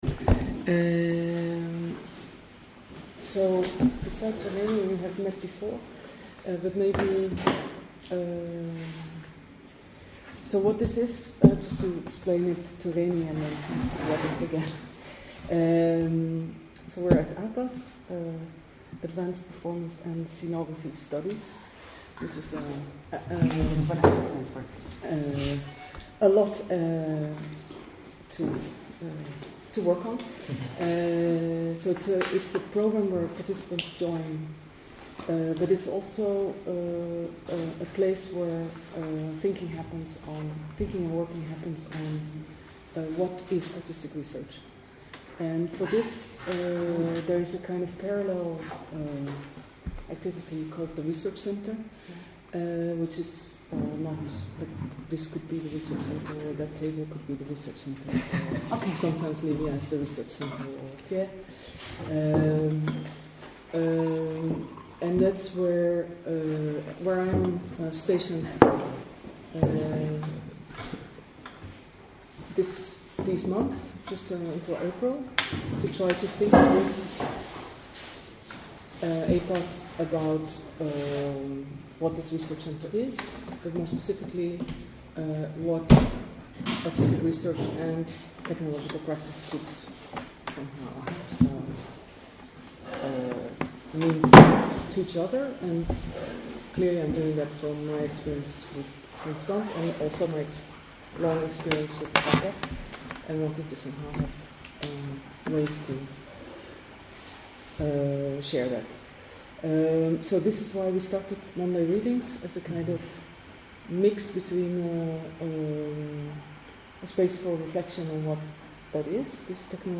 To understand the implications of this “delegation of hosting”, we will look together at different computers that act as servers, talk about where they are located, who maintains them, and why this all matters. Followed by a collective reading of texts by Muriel Combes and Invisible Committee.